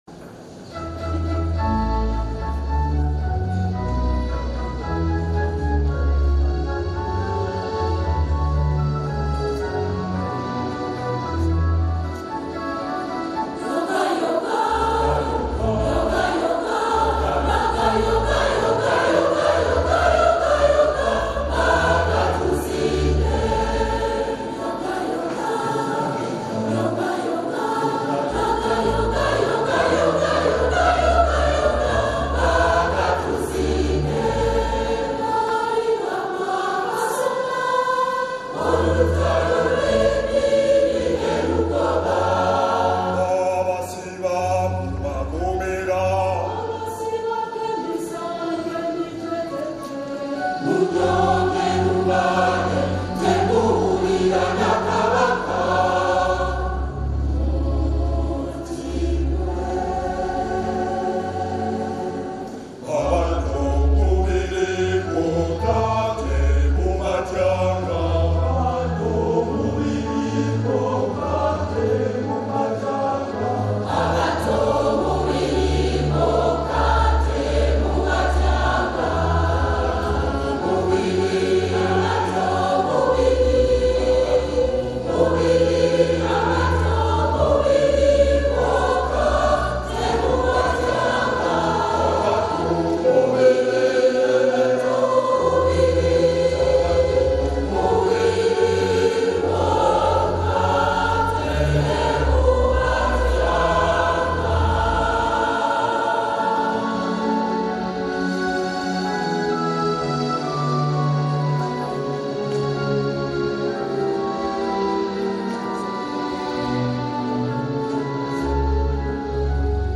lively and uplifting song
a Catholic choir from the Diocese of Lugazi in Uganda